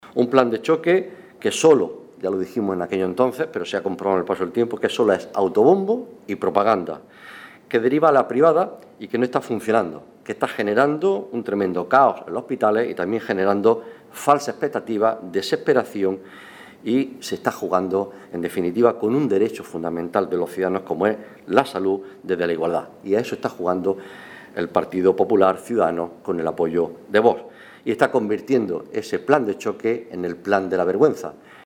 190812 Foto PSOE Noemí Cruz y Juan Carlos Perez Navas hoy en rueda de prensa